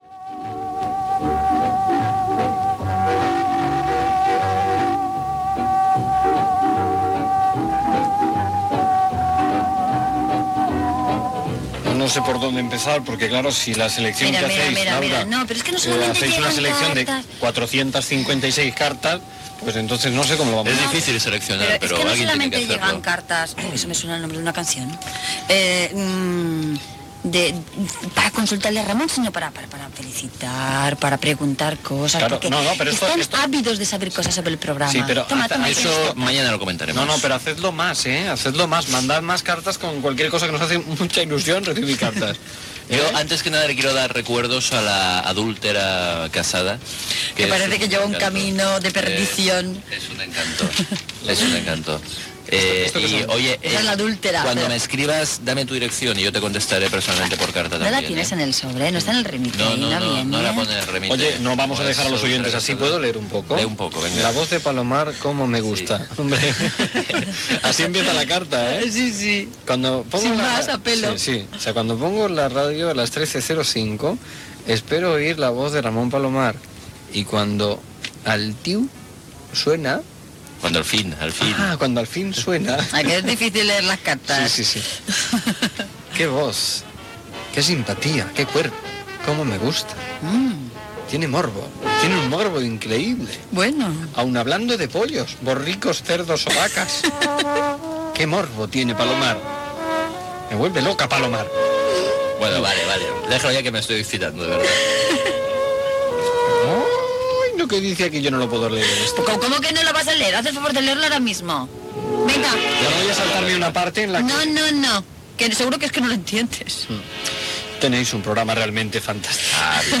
Hacia el 2000 Gènere radiofònic Entreteniment
Banda FM